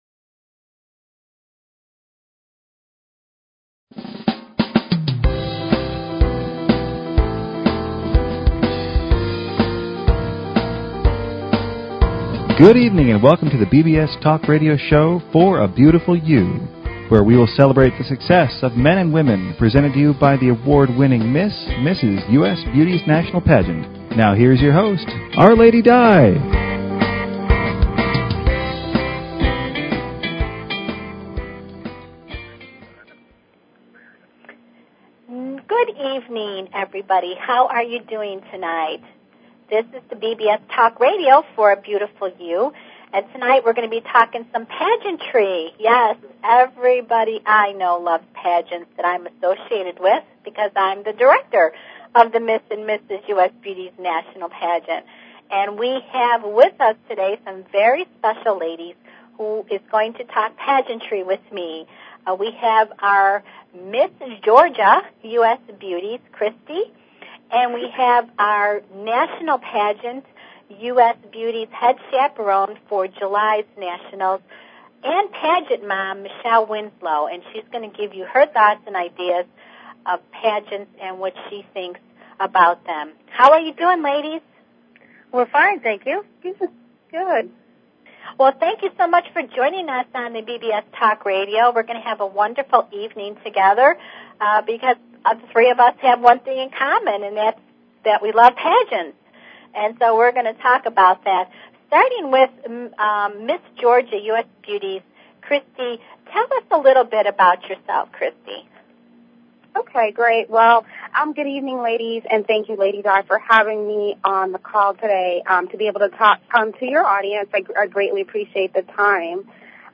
Talk Show Episode, Audio Podcast, For_A_Beautiful_You and Courtesy of BBS Radio on , show guests , about , categorized as